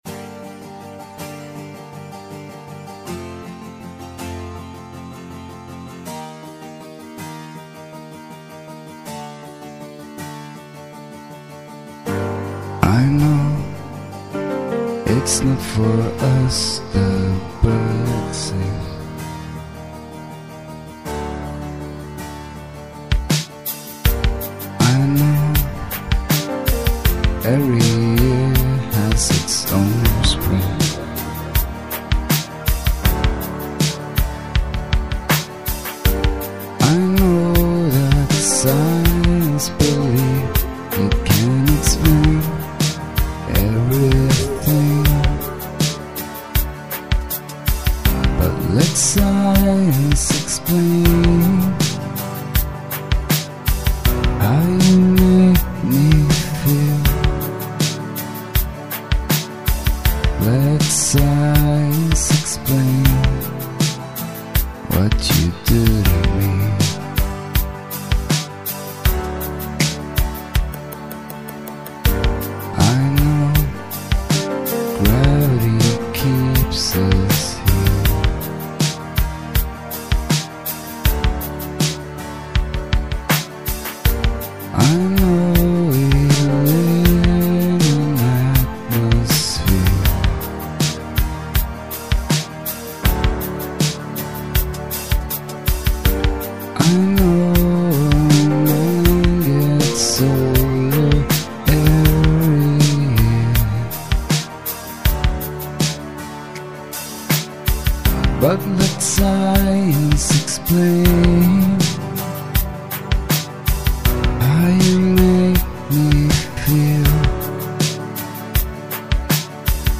(Ballad)